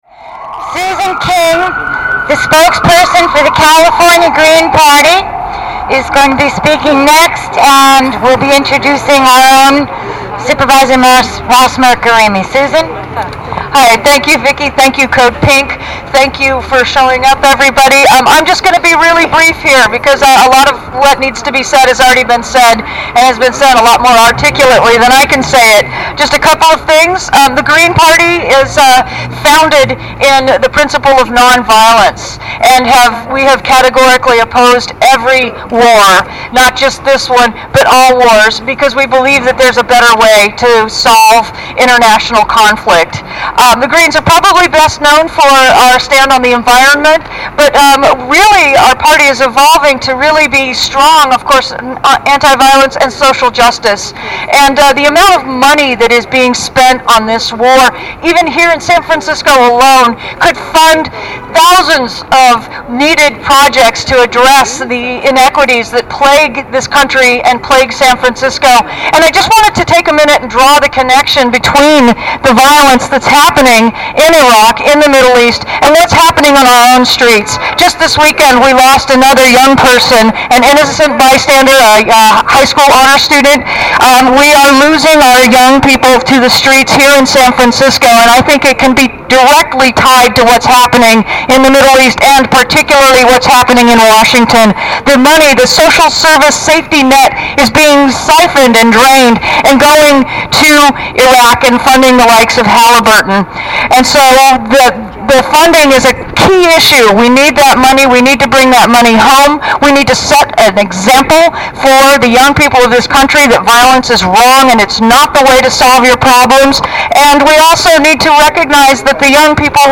Meeting at the foot of San Francisco's Federal Building this morning, San Francisco Supervisors Ross Mirkarimi and Chris Daly joined other speakers to demand that Congresswoman and Speaker of the U.S. House of Representatives Nancy Pelosi honor their resolution and the opinion of a majority of citizens to discontinue funding military operations and hold the Bush administration accountable for its total failure in Iraq.
Following are some more photos of speakers in chronological order with short mp3 recordings of their statements.